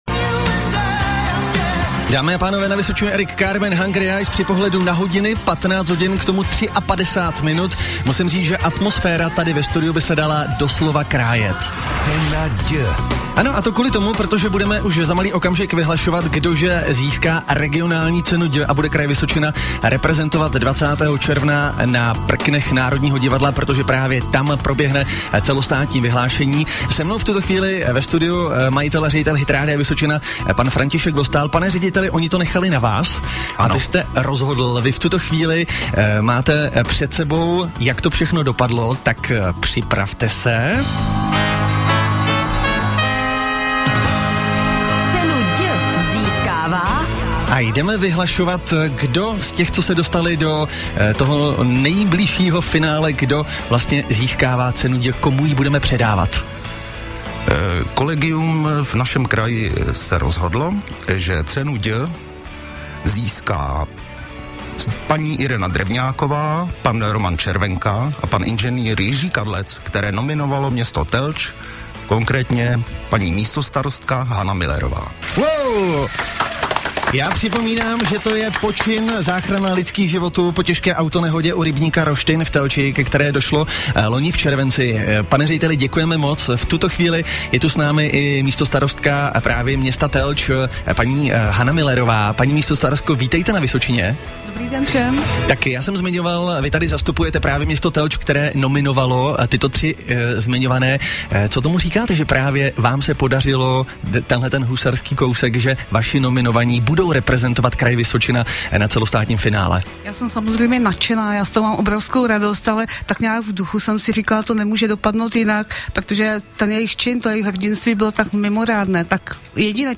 11. dubna 2013 Hitrádio Vysočina 2013, živý vstup s vítězi